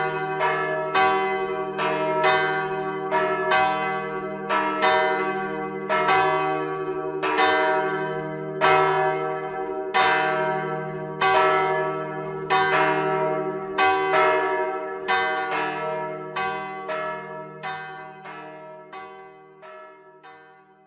Ook de klokken van Heel hebben door de samenstelling van het materiaal een warme klankkleur en een lange uitklinktijd (nagalm).
Het luiden van alle klokken noemt men het plenum of volgelui.
Luister naar het volgelui van de klokken.
Kerkklokken-Heel-Plenum-Volgelui.mp3